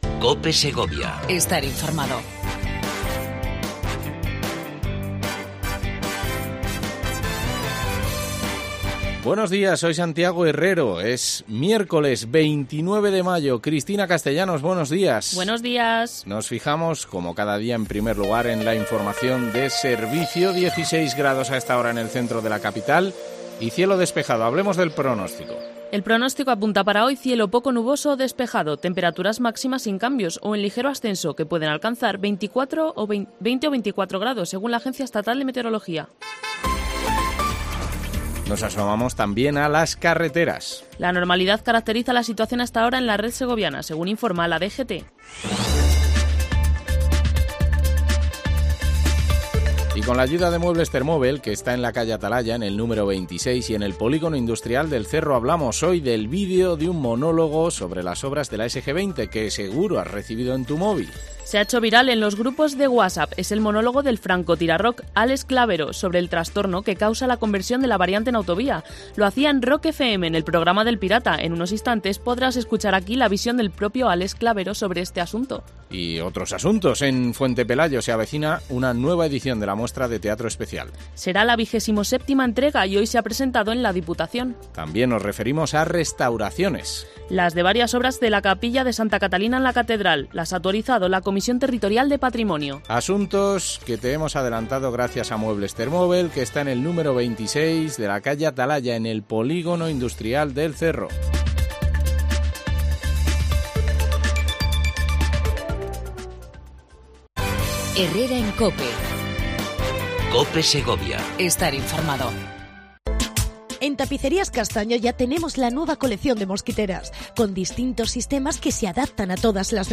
Entrevistamos